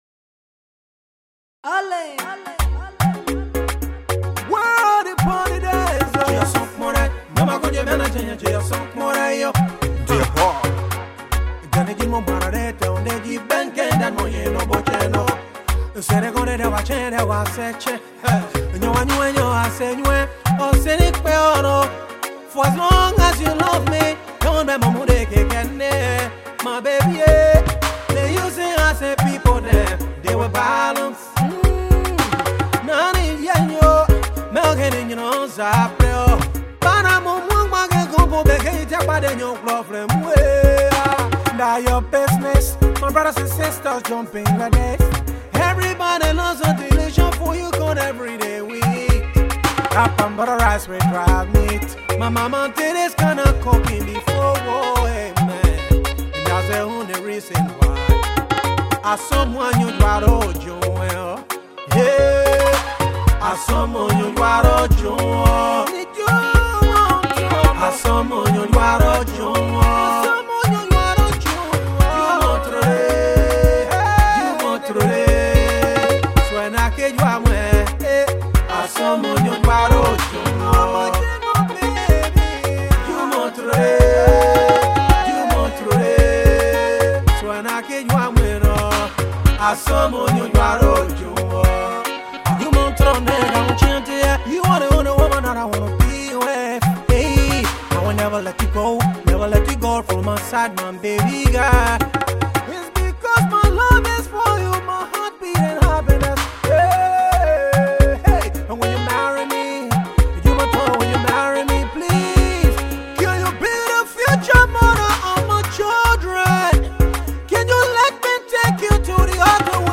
traditional love music